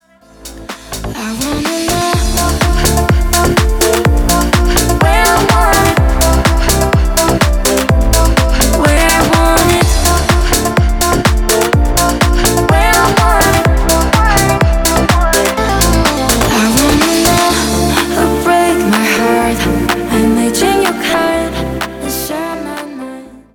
поп
красивые
Классная танцевальная песня